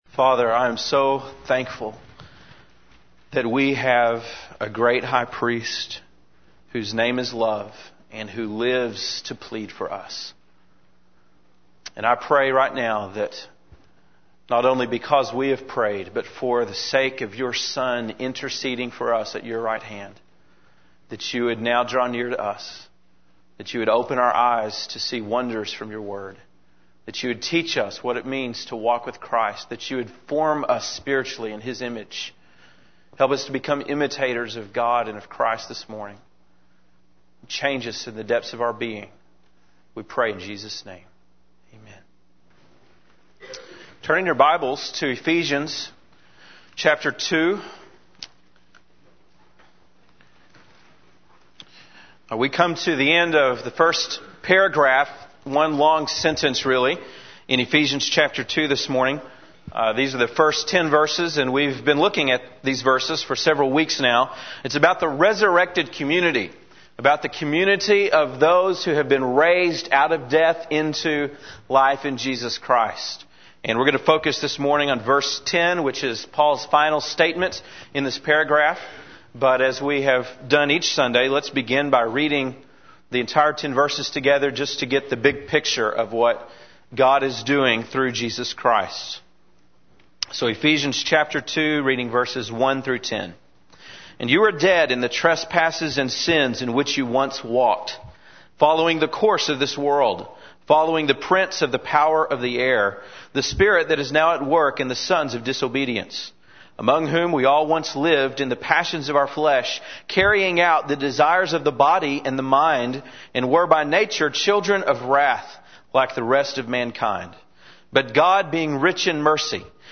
December 12, 2004 (Sunday Morning)